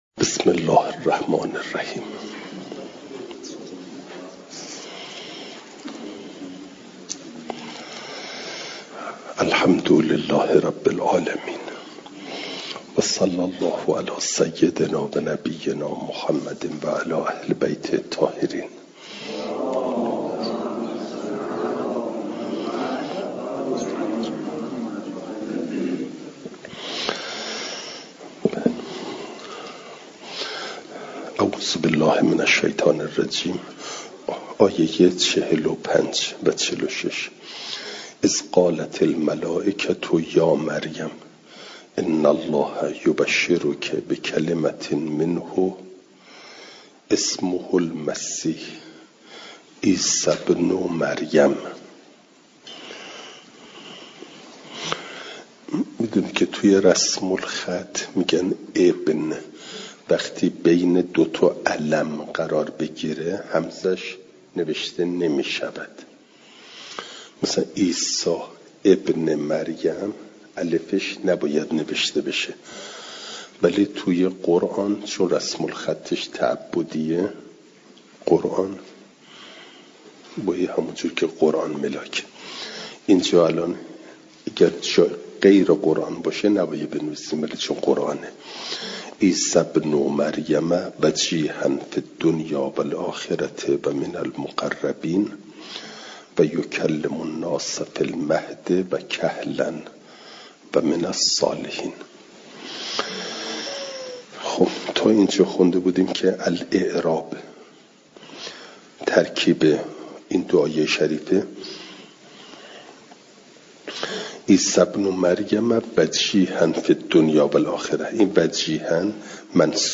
صوت تفسیر آیات چهل و پنج تا چهل و هفت سوره مبارکه آل عمران از درس تفسیر مجمع البیان